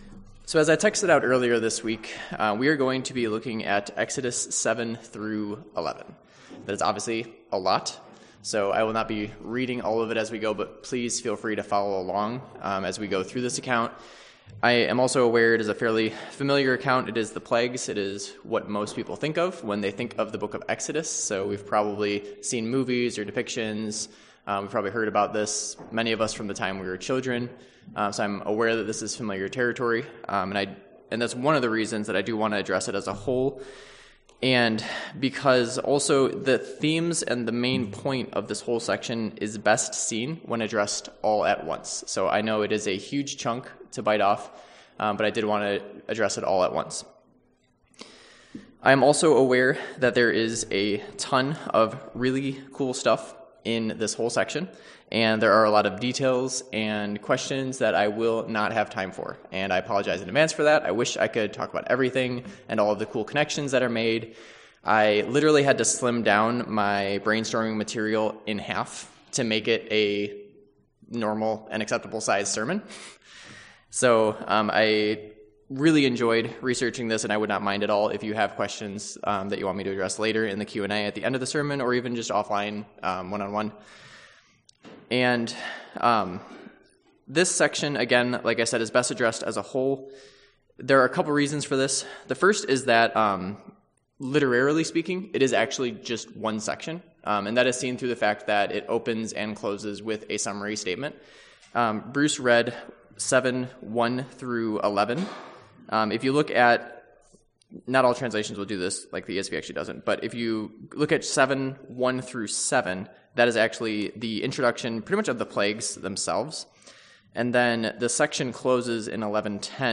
Exodus 7-11 Service Type: Worship Service « Matthew 1-2